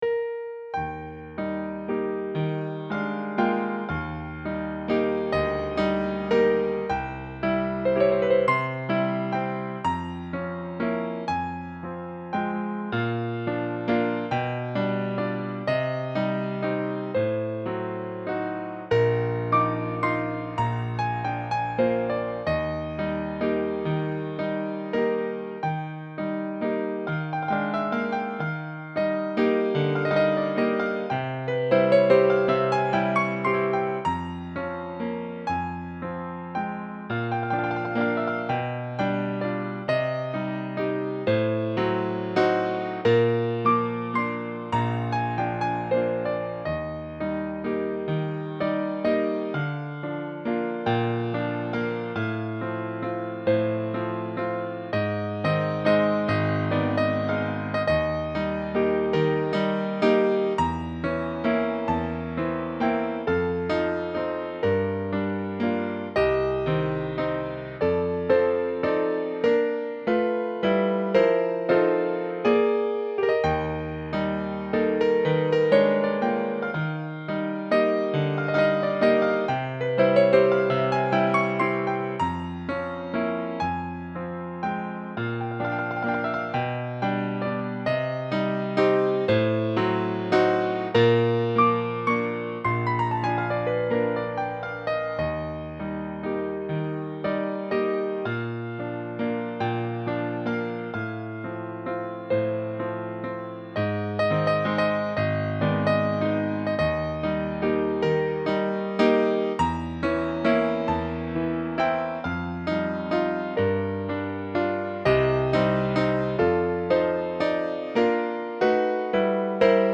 Колыбельная 2